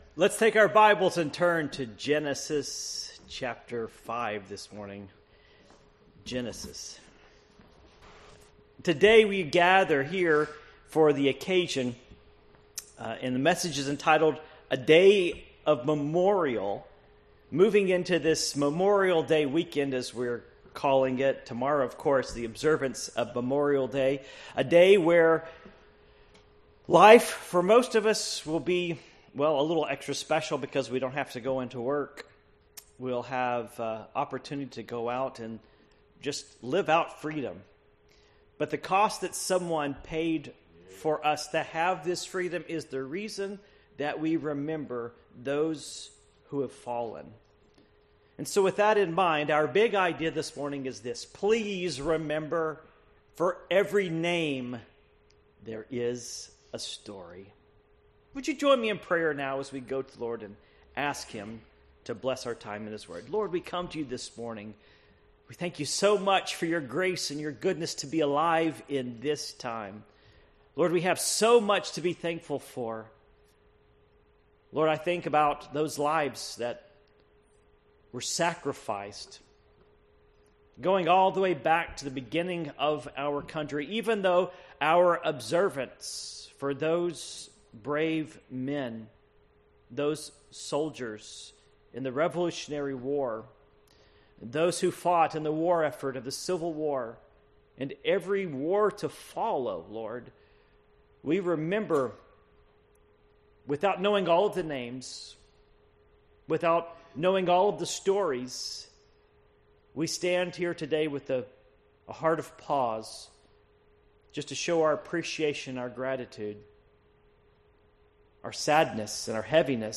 The Ministry of the Encourager Passage: Genesis 5:1-32 Service Type: Morning Worship Genesis 5:1-32 1 This is the book of the generations of Adam.